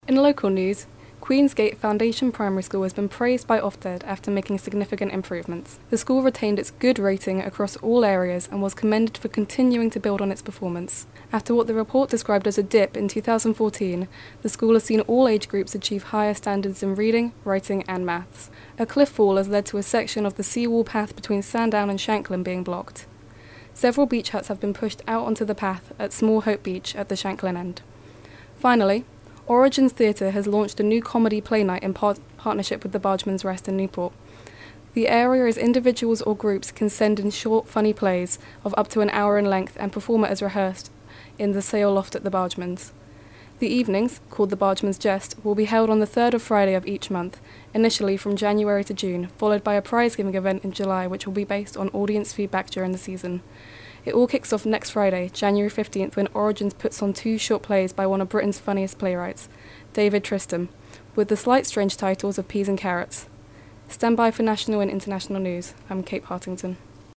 First News Read Out